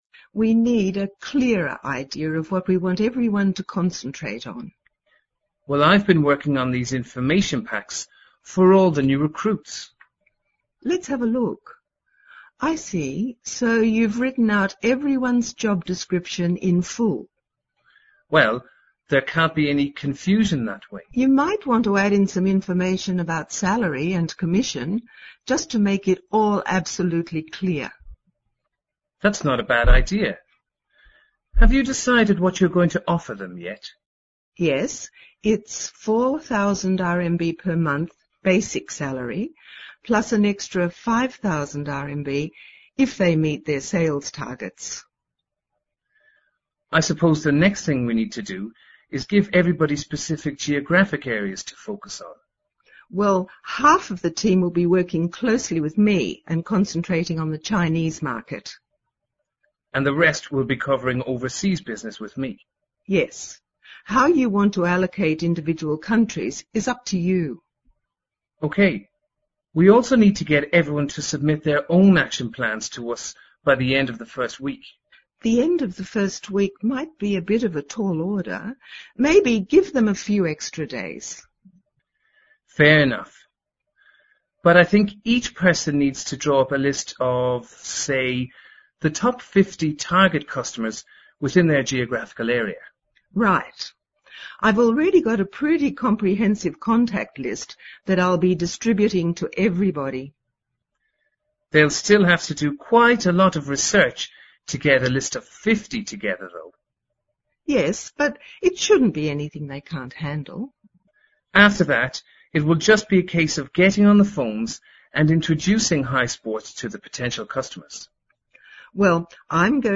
Listen to the dialogue between Head of Sales (HS) and Sales Manager (SM)